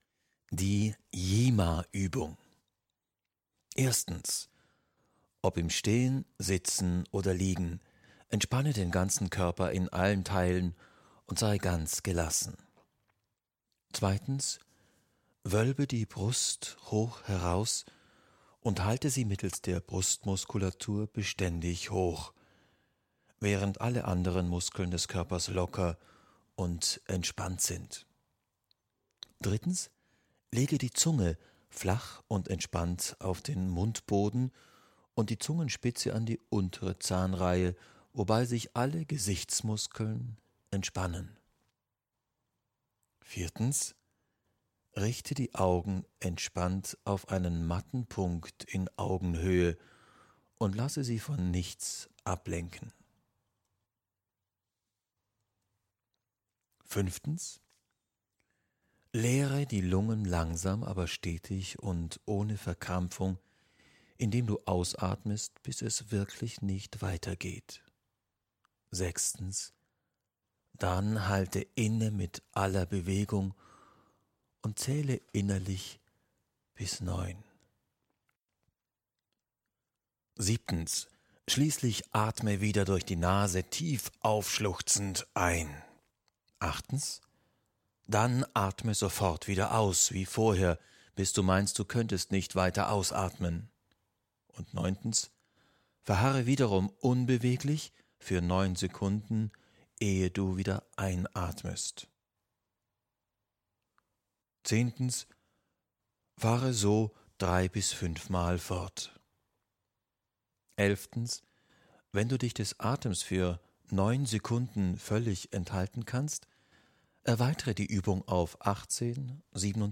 (Gefällt Ihnen der gesprochene Text? Haben Sie Interesse an einem kompletten Hörbuch der Atemkunde? Schreiben Sie uns: Kontakt )